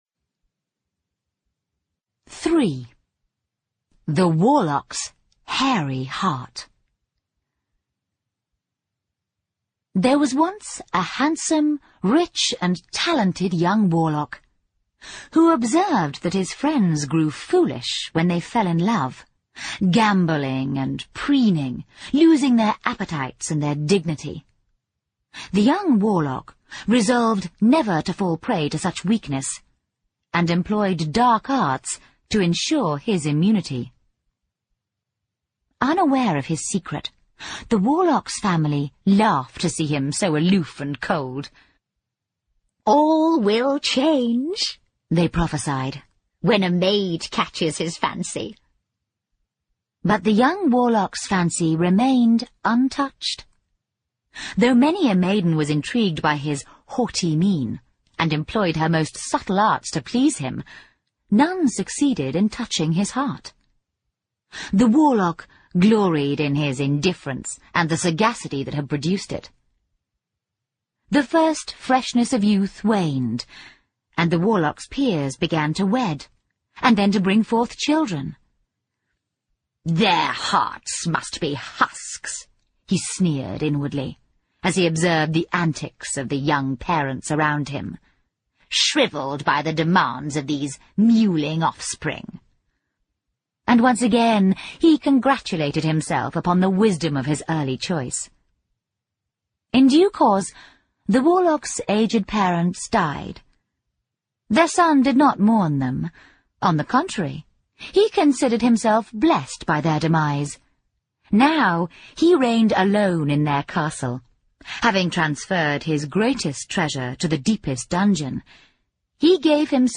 在线英语听力室诗翁彼豆故事集 第11期:男巫的毛心脏(1)的听力文件下载,《诗翁彼豆故事集》栏目是著名的英语有声读物，其作者J.K罗琳，因《哈利·波特》而闻名世界。